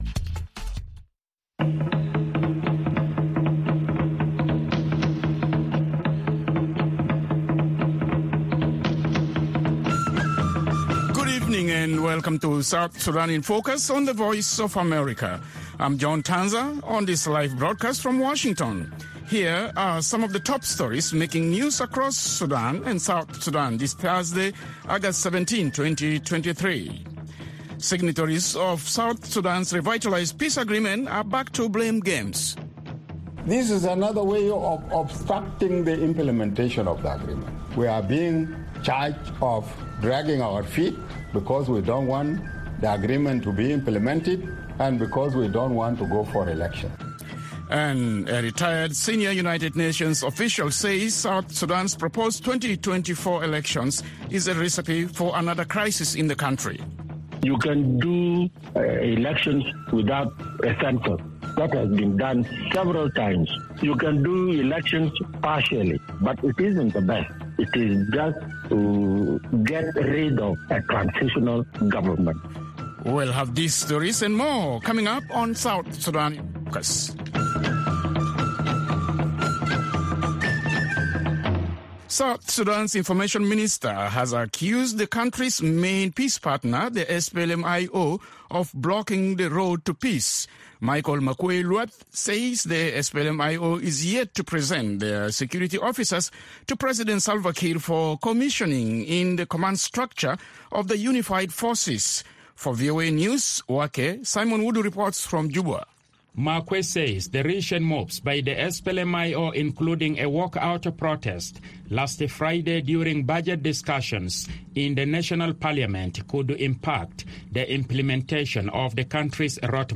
South Sudan in Focus airs at 7:30 pm in Juba (1630 UTC) and can be heard on FM stations throughout South Sudan, on shortwave, and on VOA’s 24-hour channel in Nairobi at 8:30 pm.